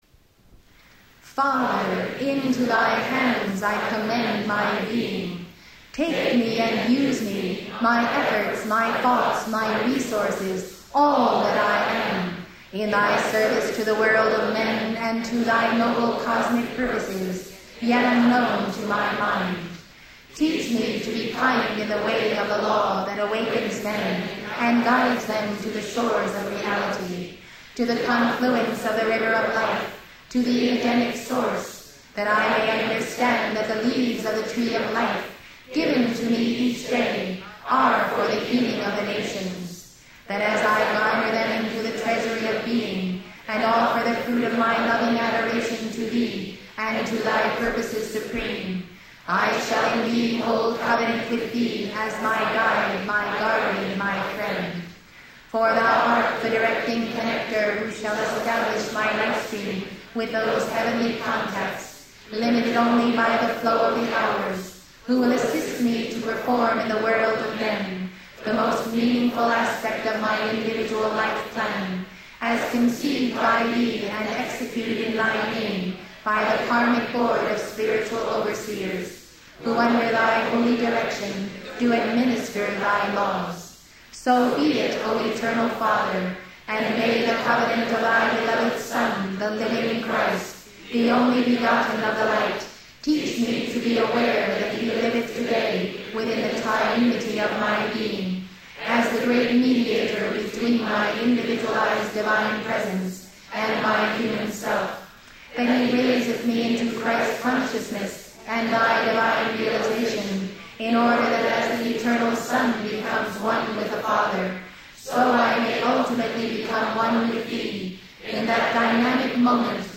Give this meditation by the El Morya aloud with Elizabeth Clare Prophet